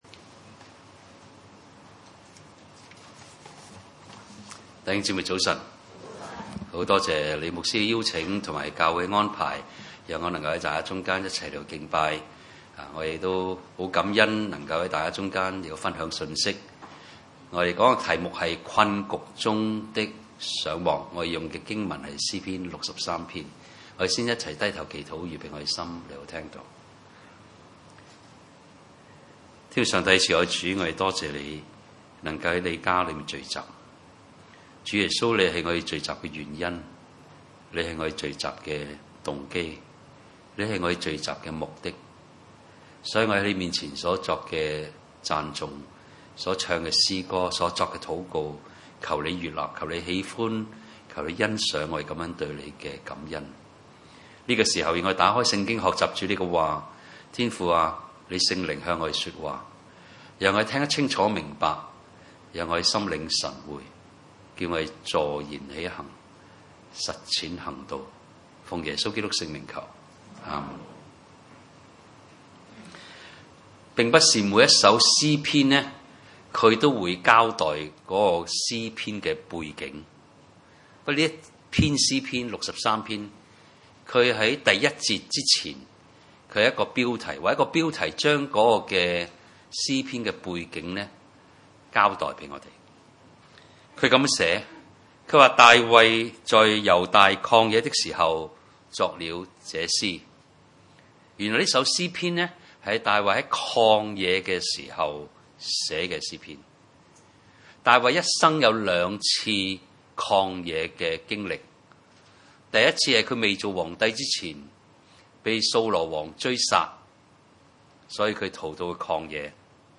詩篇第63篇 崇拜類別: 主日午堂崇拜 1（大衛在猶大曠野的時候，作了這詩。）